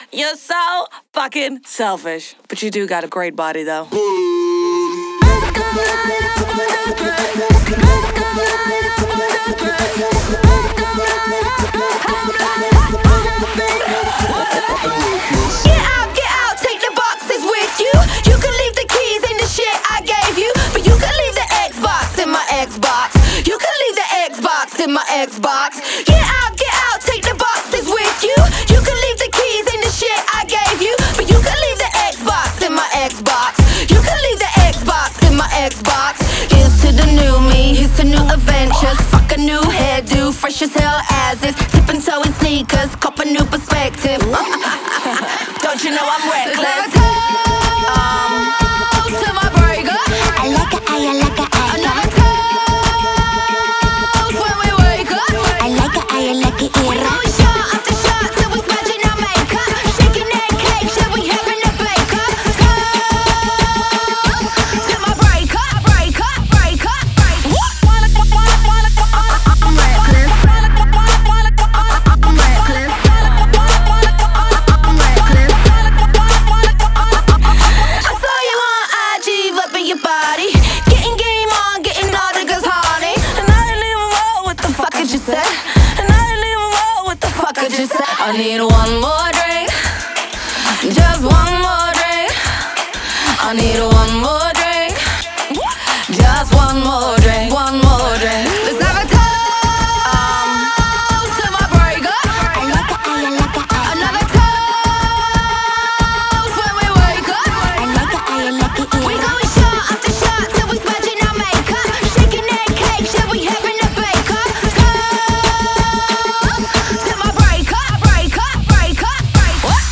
это яркая и энергичная композиция в жанре поп и EDM